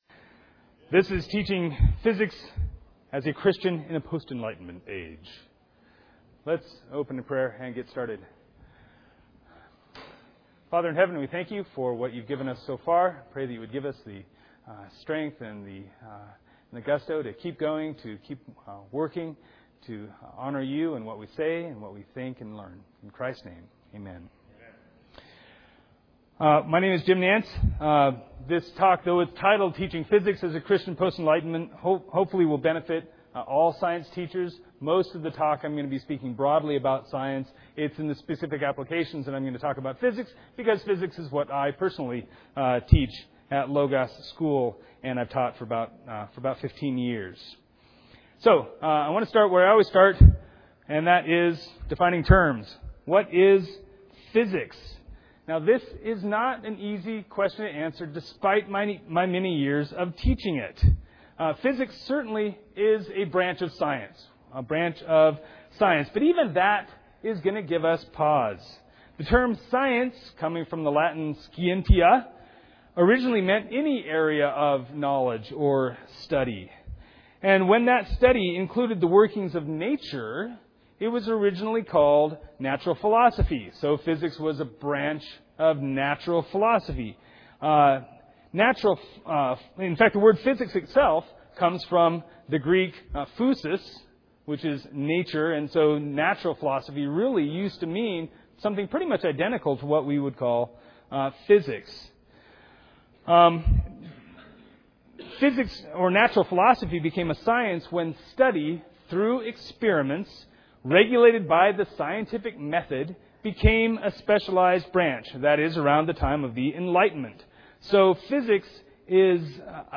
2007 Workshop Talk | 0:44:43 | 7-12, Science
The Association of Classical & Christian Schools presents Repairing the Ruins, the ACCS annual conference, copyright ACCS.